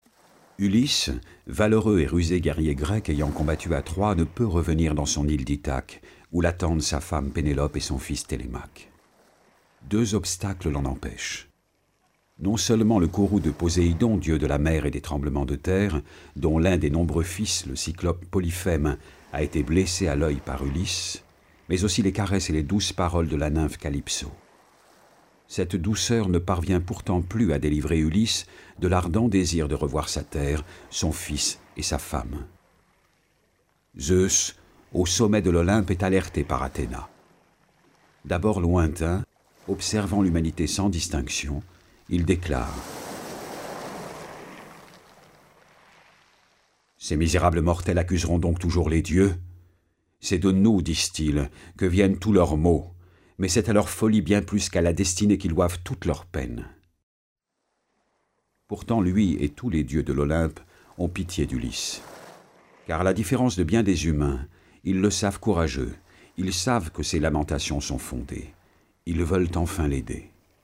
Click for an excerpt - L'odyssée de Homère